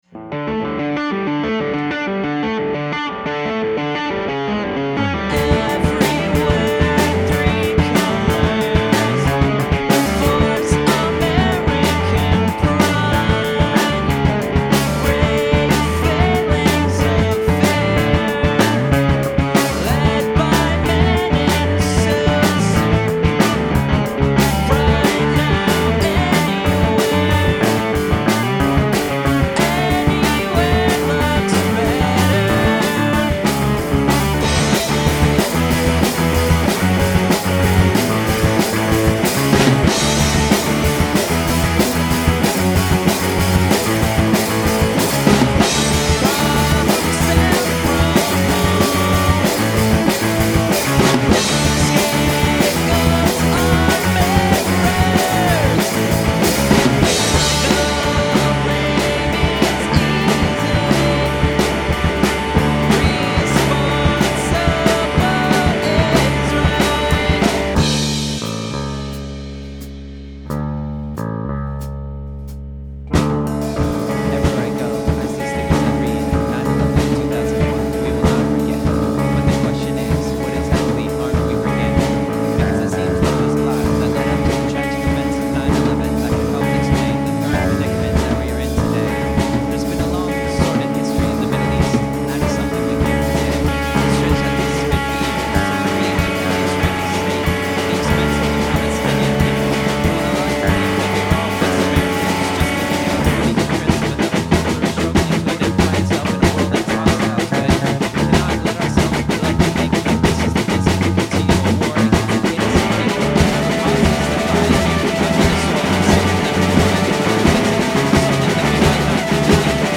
recorded at bartertown studios